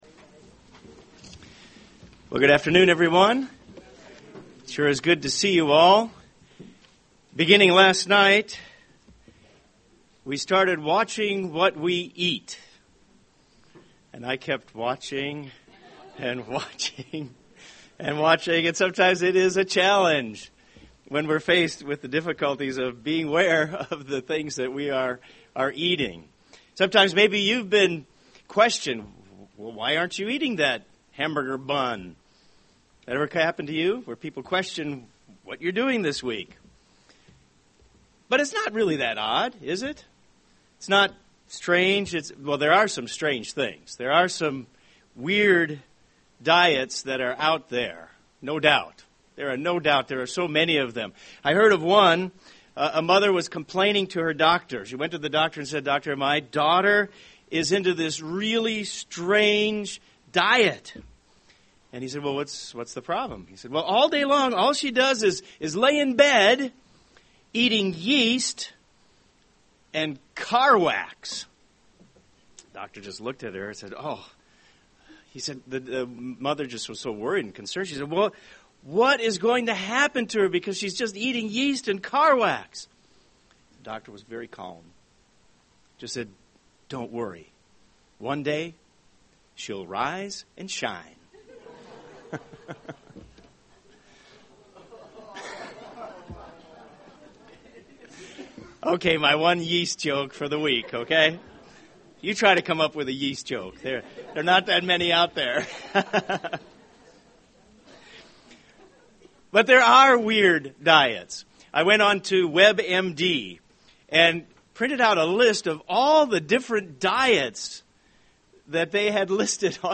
Sermon during the first day of Unleavened Bread